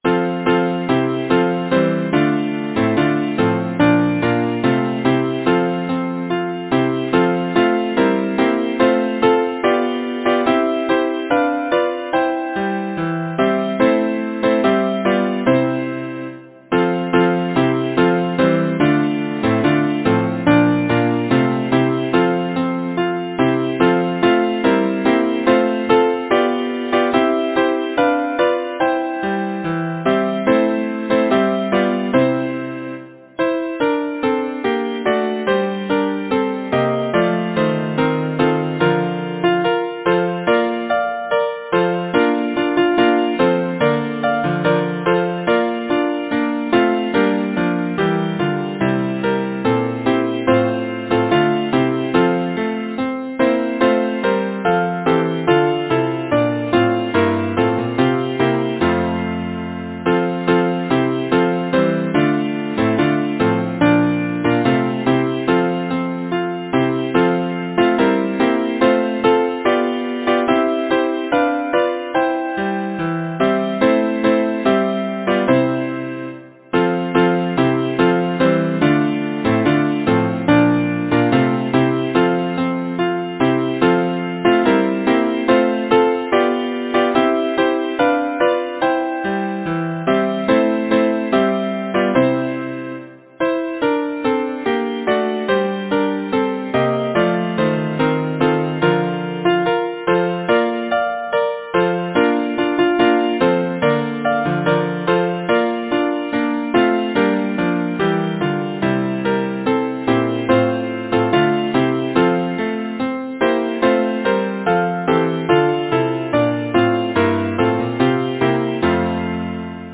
Title: When Spring comes round Composer: Henry Thomas Smart Lyricist: Frederick Enoch Number of voices: 4vv Voicing: SATB Genre: Secular, Partsong
Language: English Instruments: A cappella